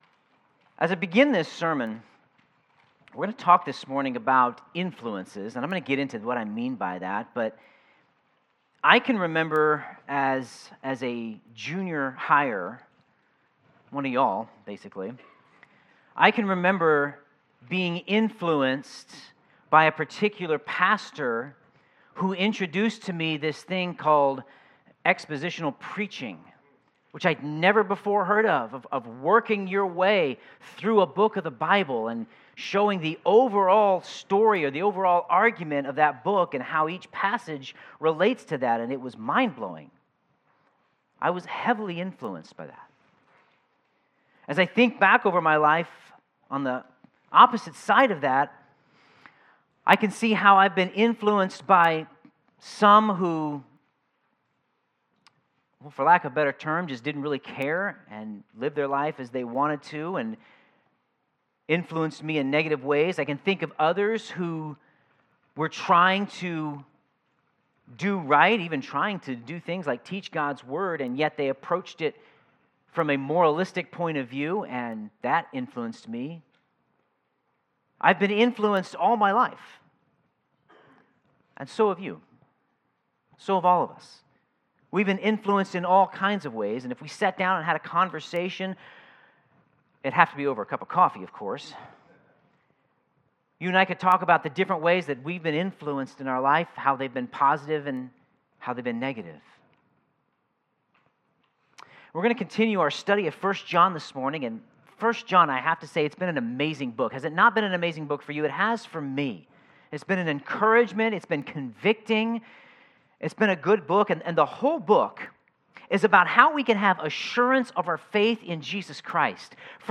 Sermon Notes In order to not be fooled by the wrong influences, it is important to be able to discern the influences in our lives.